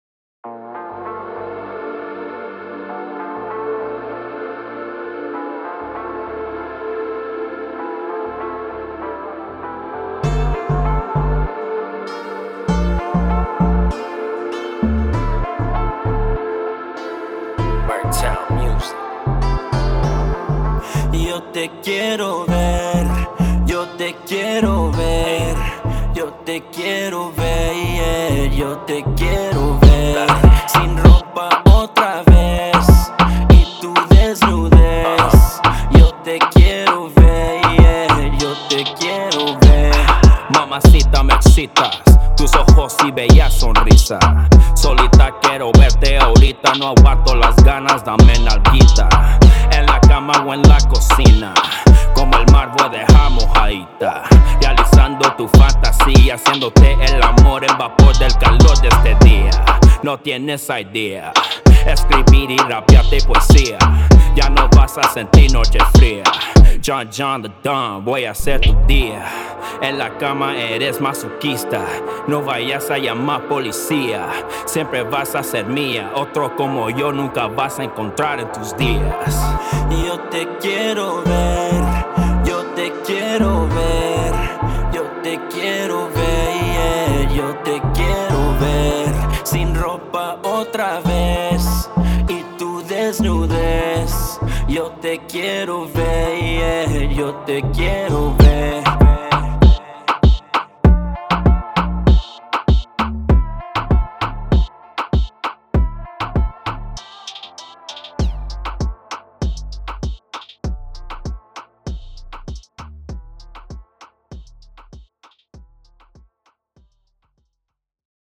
Reggeaton